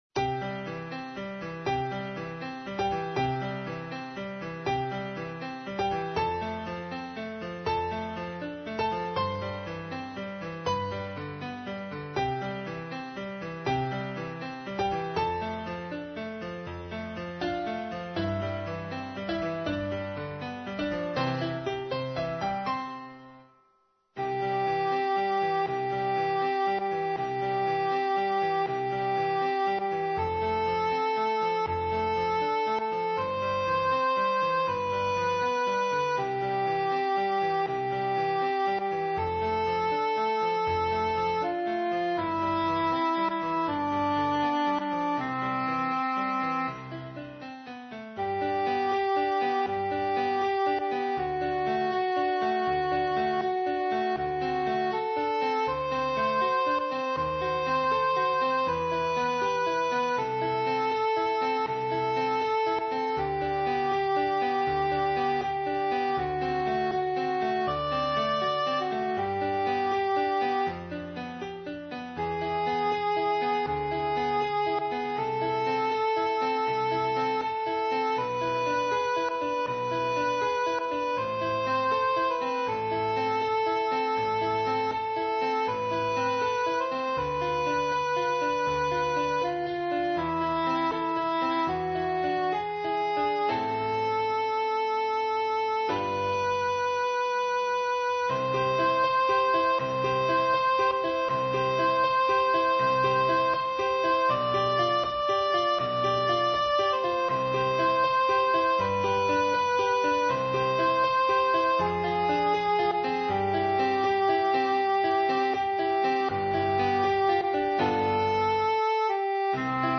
Nous disposons de la partition d'un cantique maçonnique dont le compositeur est désigné comme Marc Ate.